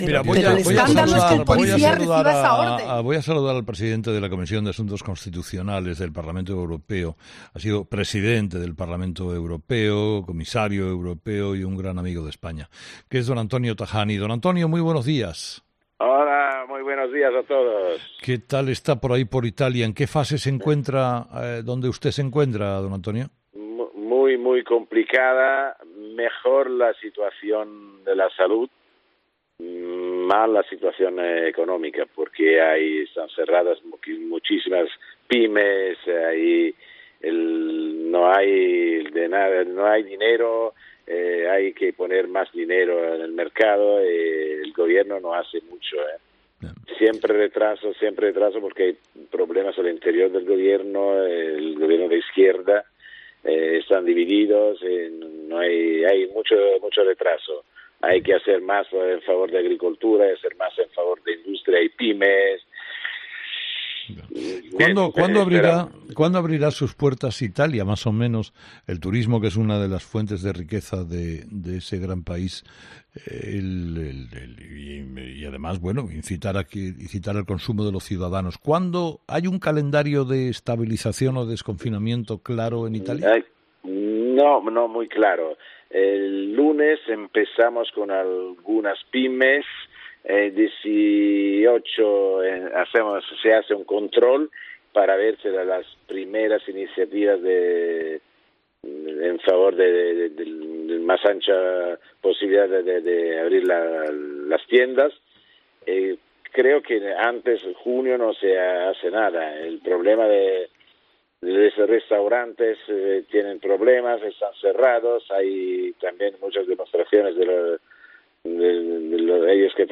Así lo ha manifestado en ‘Herrera en COPE’ al hablar de un “momento muy difícil pero creo que al final se puede ganar este partido y defender la UE”.